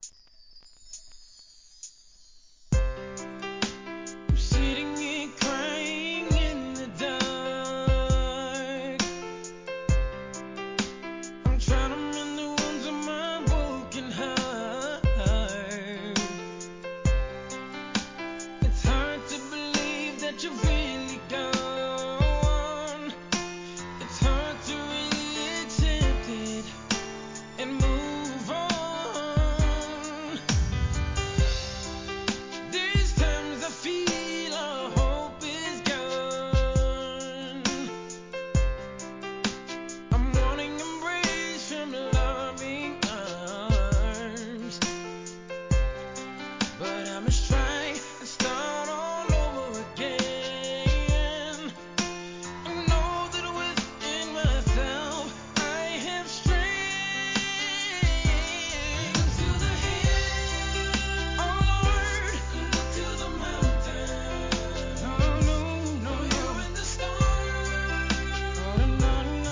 HIP HOP/R&B
SLOW JAMが揃います♪